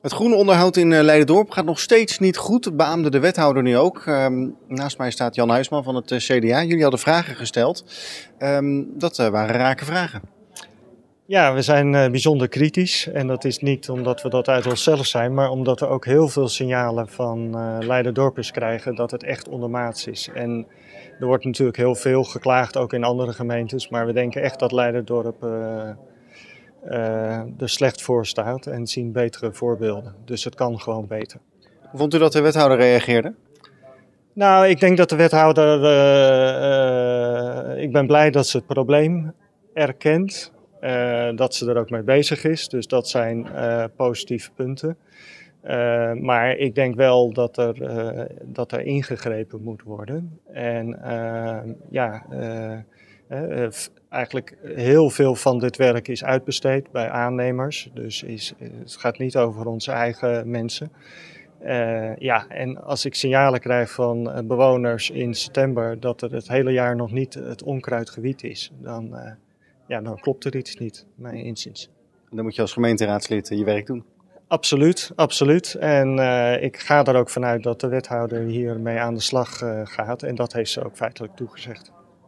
Jan Huisman van het CDA over het groenonderhoud.